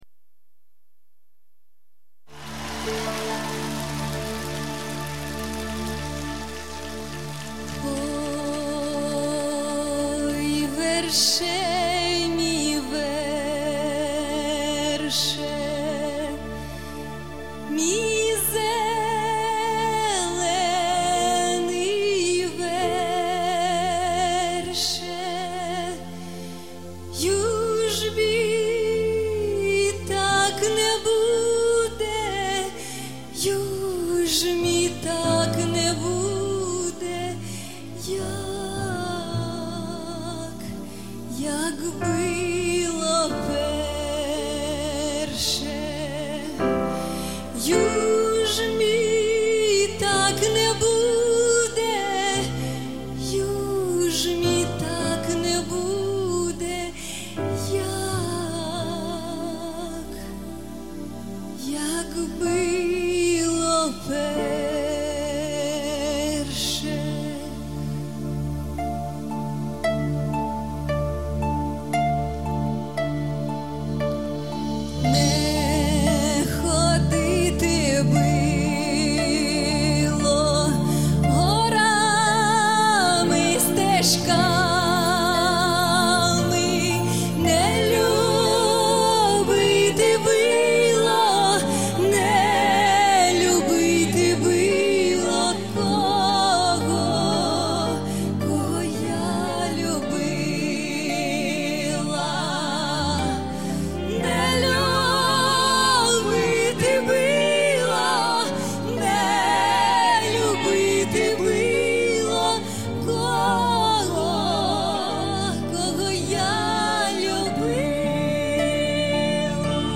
И голос красивый.